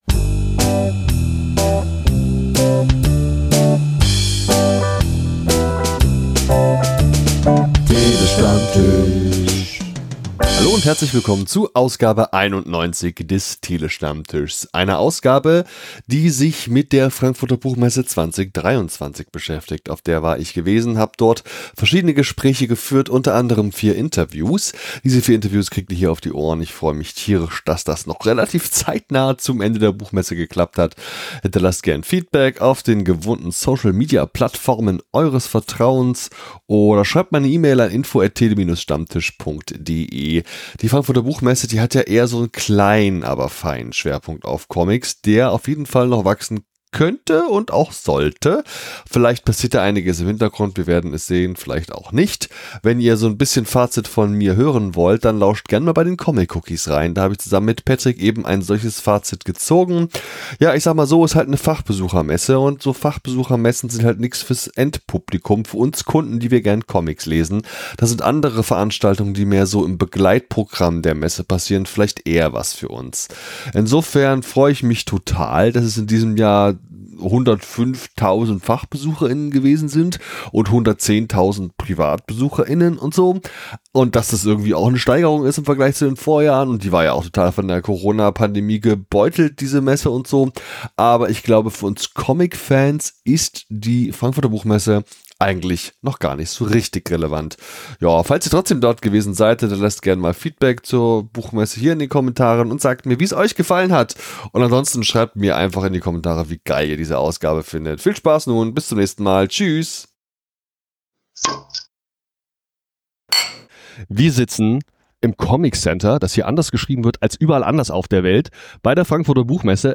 TST091 - Interviews auf der Buchmesse Frankfurt 2023 ~ Der Tele-Stammtisch - Comictalks & Interviews Podcast